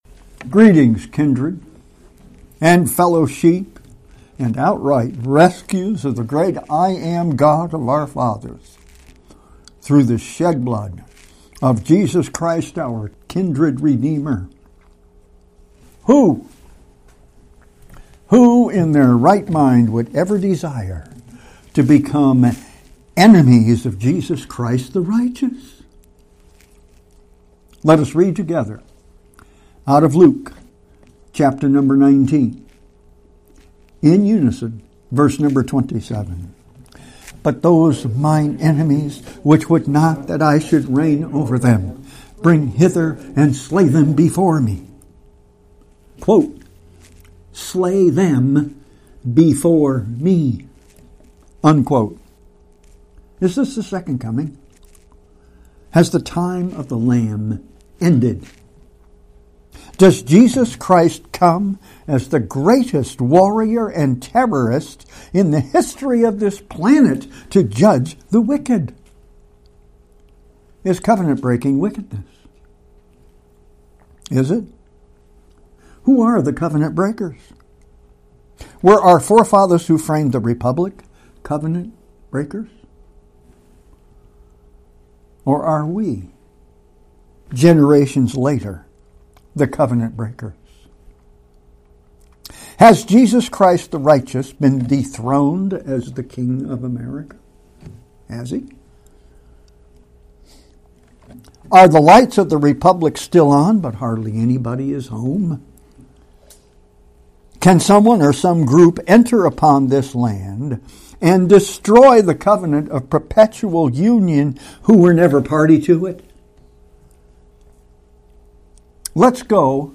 2022 Sermons 00:00 Enemies Again - Part 2.mp3 ENEMIES AGAIN! PART 2